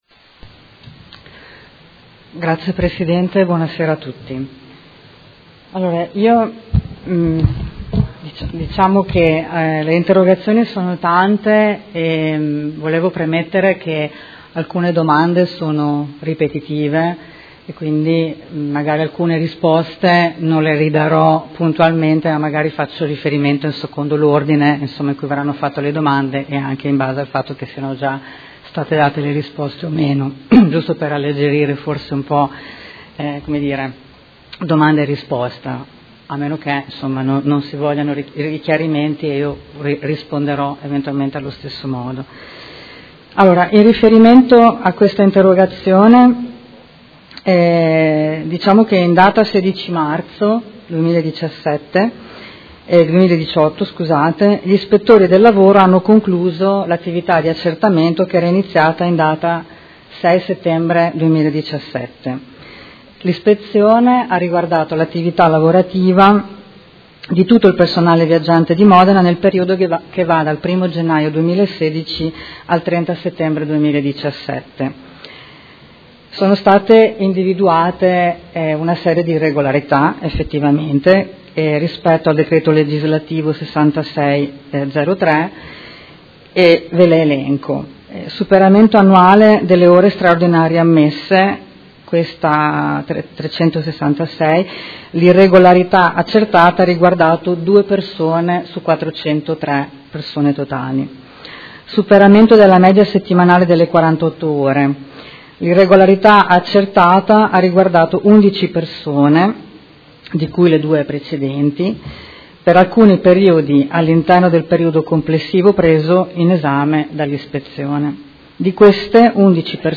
Seduta del 21/06/2018 Risponde. Interrogazione dei Consiglieri Scardozzi, Rabboni, Bussetti e Bortolotti (M5S) avente per oggetto: SETA sanzionata per uso improprio del suo personale - Prima firmataria Consigliera Scardozzi